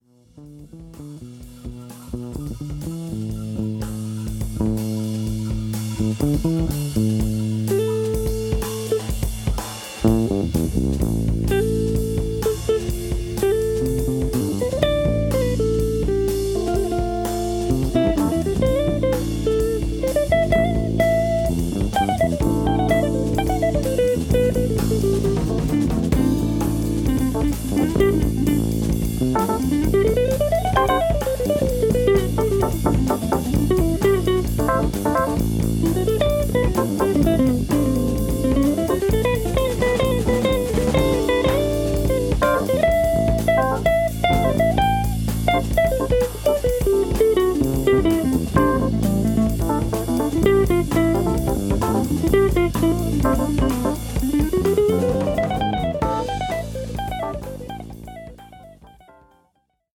NYのS&SW/ギタリスト/ピアニスト